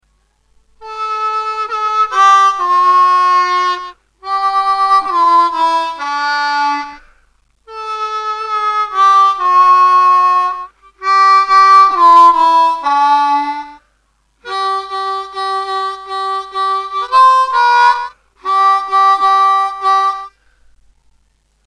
3D”   3D”..3B..2D’   (with hand vibrato)
2D   2D”..2B..1D (with gentle hand vibrato)